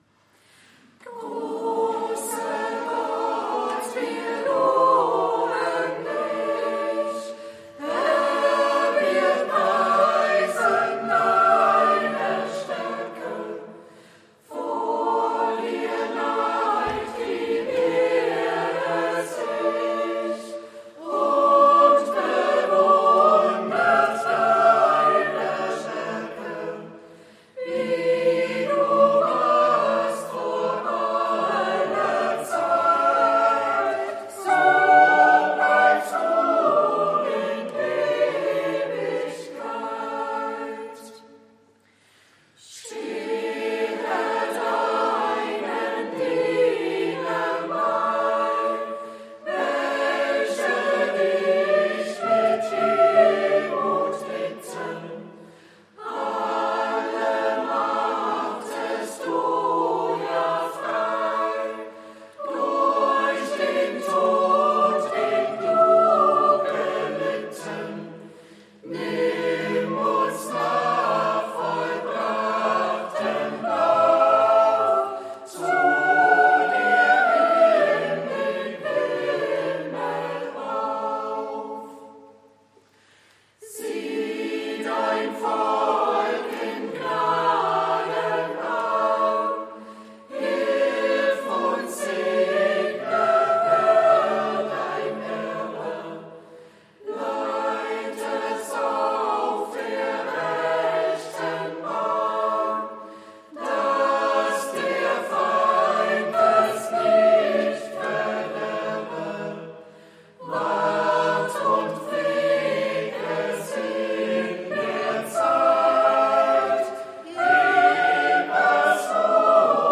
Großer Gott, wir loben dich... Chor der Ev.-Luth. St. Johannesgemeinde Zwickau-Planitz
Audiomitschnitt unseres Gottesdienstes vom Sonntag Lätare 2024